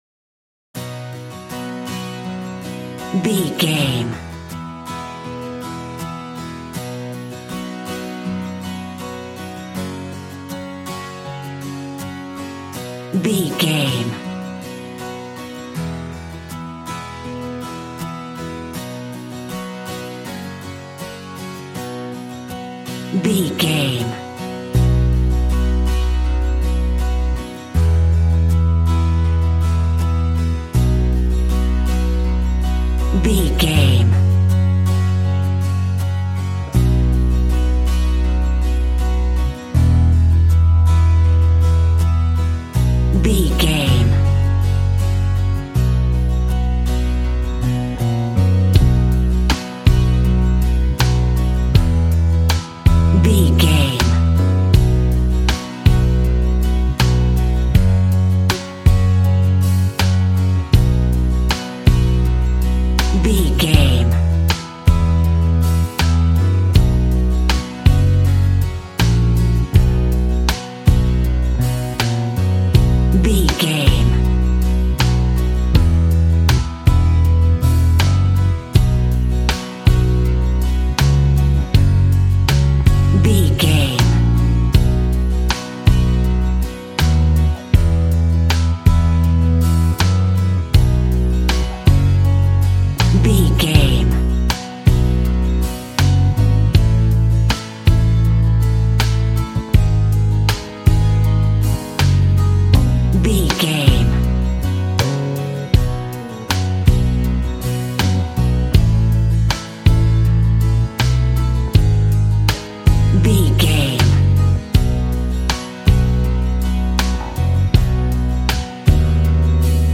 Ionian/Major
romantic
sweet
happy
acoustic guitar
bass guitar
drums